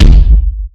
WR_TypeF_Walking.wav